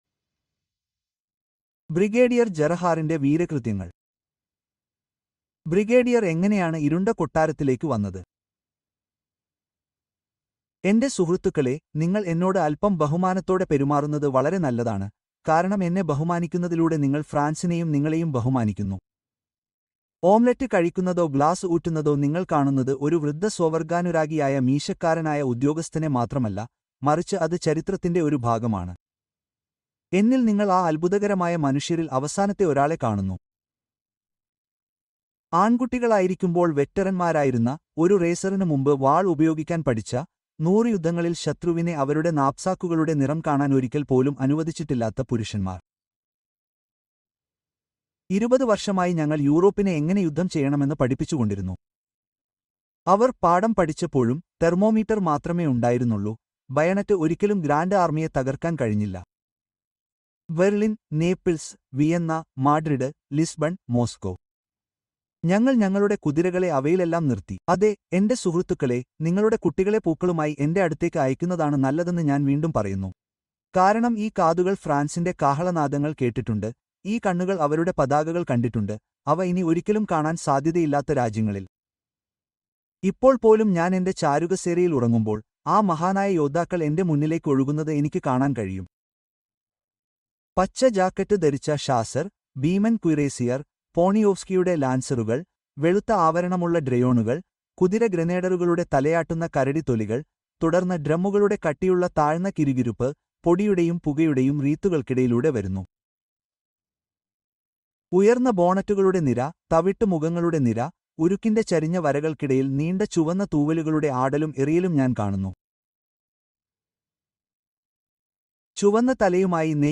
Casting the Runes: M.R. James’ Chilling Ghost Story (Audiobook)